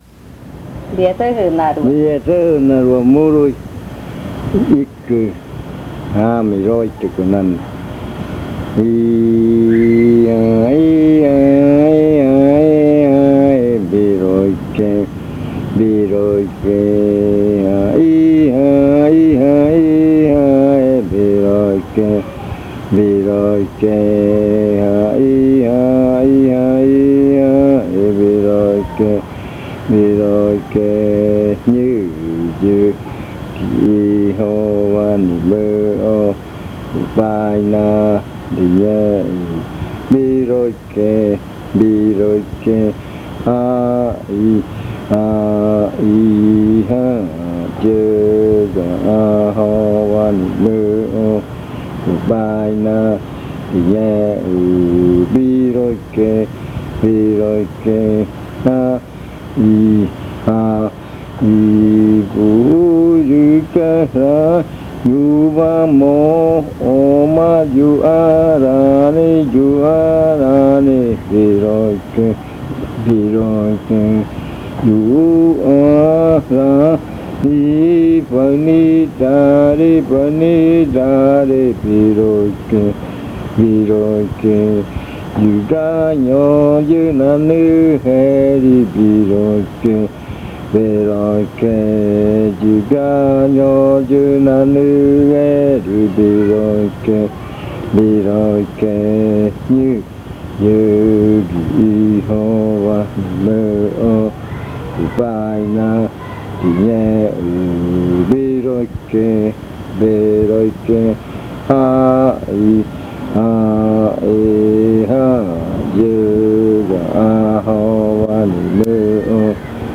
Leticia, Amazonas
Canción de arrimada (zɨjɨa rua), la que abre la fiesta. La canta el contendor principal. En el baile la gente va a tomar la cahuana, a comer y a divertirse, bailando toda la noche hasta amanecer.
Entry chant (zɨjɨa rua), the one that opens the ritual. It is sung by the main ceremonial ally.